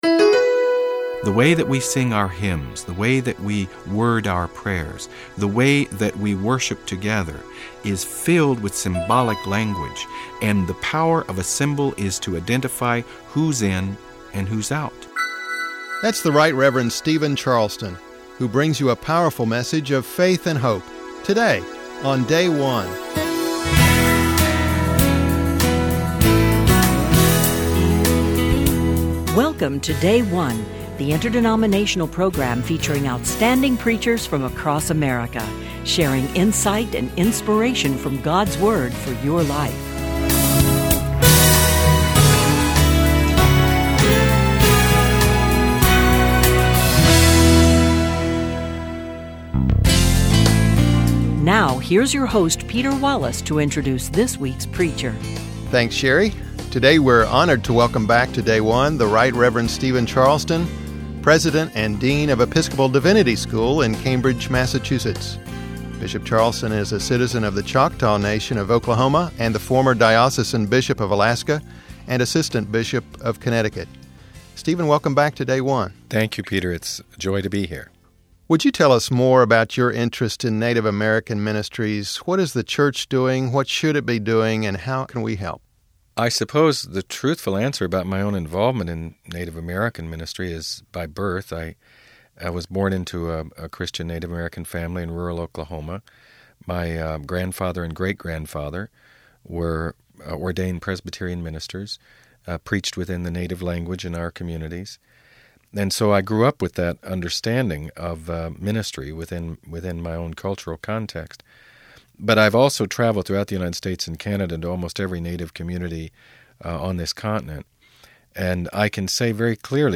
Sermon for Proper 23